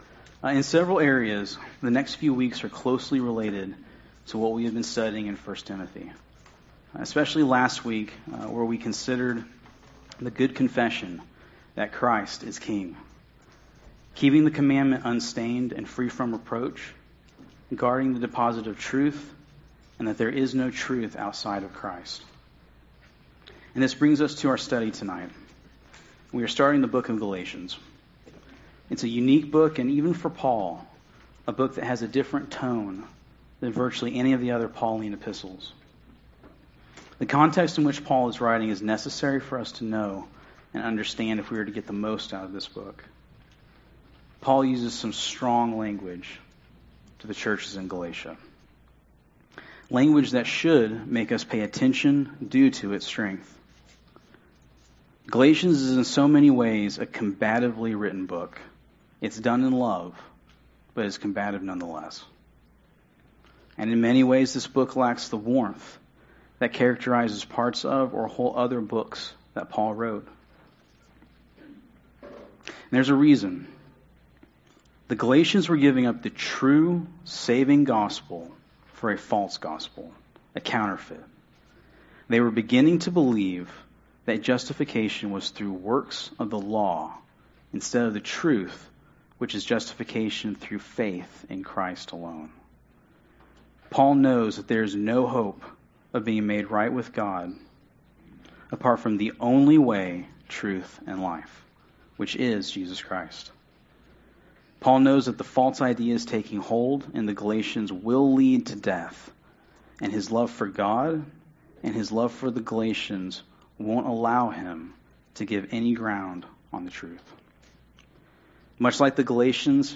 Passage: Galatians 1 Service Type: Sunday Service